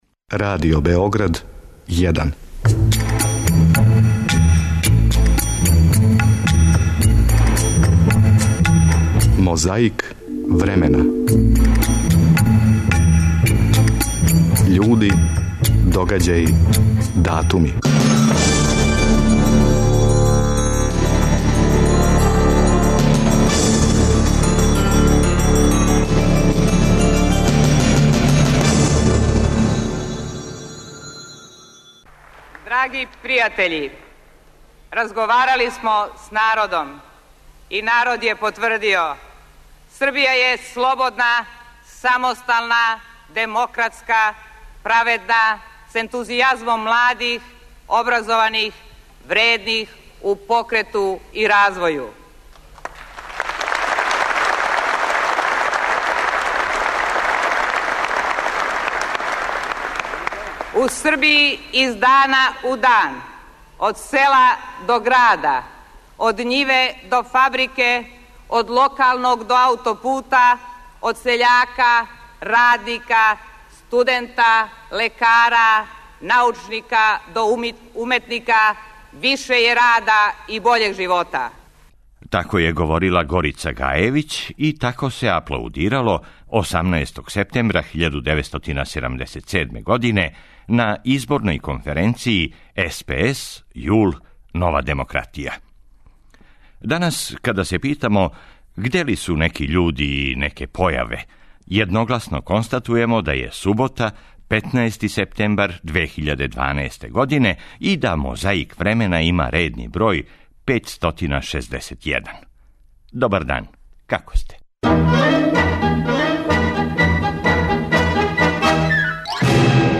Прва звучна коцкица је са изборне конференције СПС-ЈУЛ-НД, 15. септембра 1997. Године. Говорила је Горица Гајевић у Сава центру...